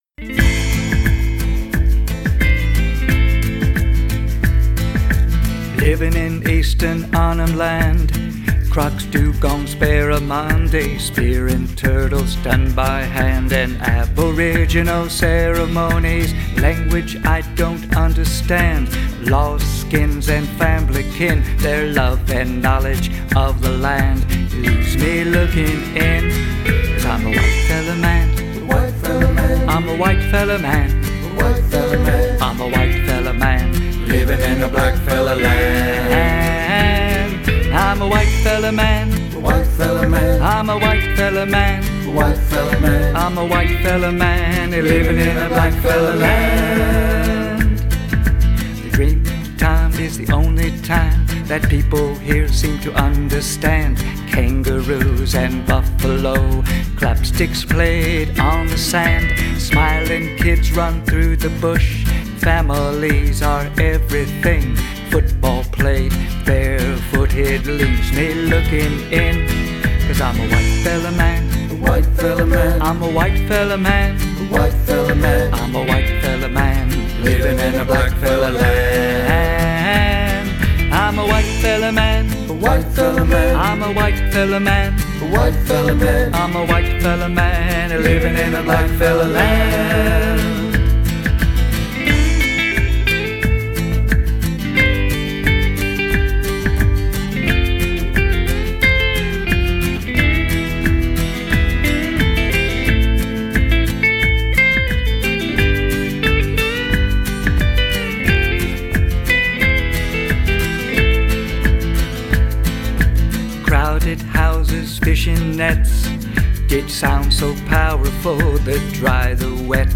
Vocals, Acoustic Guitar
Percussion, Acoustic Guitar, Backing Vocals, Bass Guitar
Electric Guitar, Backing Vocals